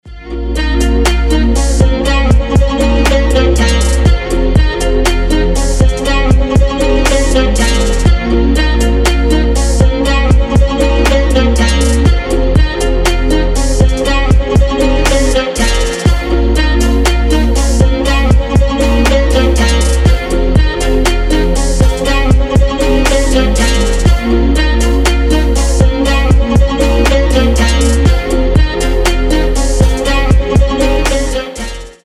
• Качество: 320, Stereo
Electronic
без слов
басы
восточные
Стиль: deep house